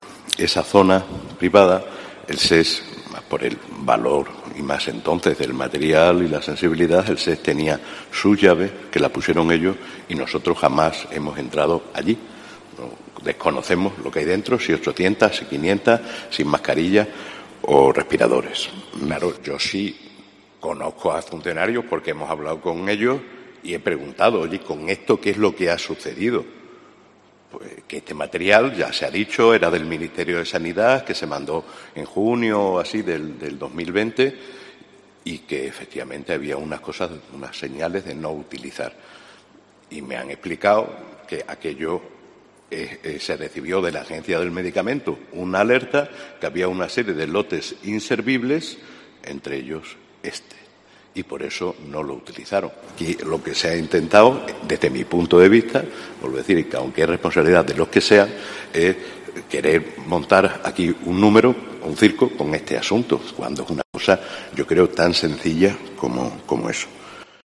Antes de la visita al delegado del Gobierno, el alcalde de Almendralejo, en el Ayuntamiento, ofreció una rueda de prensa para hablar de este asunto: “Lo que se ha intentado es querer montar un número, un circo”.